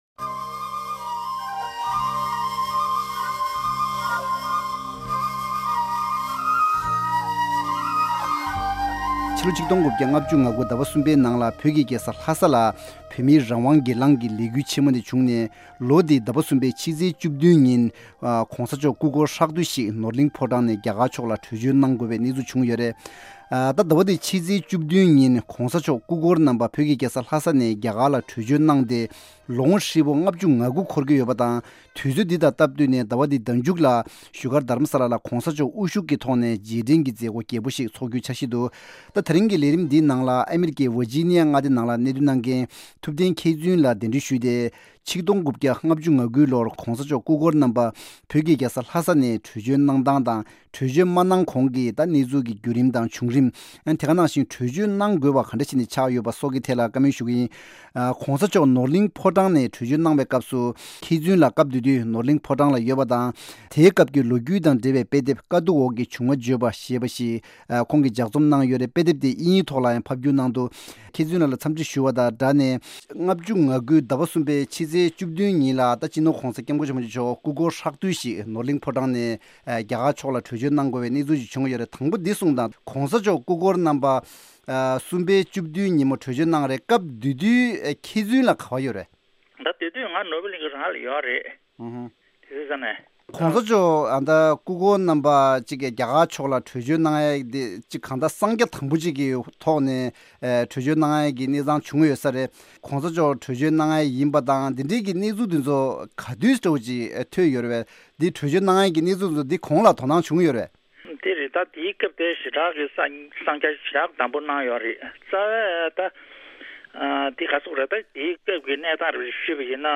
༡༩༥༩ ལོར་བོད་མིའི་རང་དབང་སྒེར་ལངས་ལས་འགུལ་སྐབས་སུ་ནོར་གླིང་ཕོ་བྲང་ནང་ལ་ཡོད་པའི་མི་སྣ་ཞིག་ལ་སྐབས་དེའི་གནས་ཚུལ་ཀྱི་བྱུང་རིམ་སྐོར་གནས་འདྲི་ཞུས་པ་ཞིག་གསན་རོགས་གནང་།